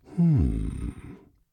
B_hmm5.ogg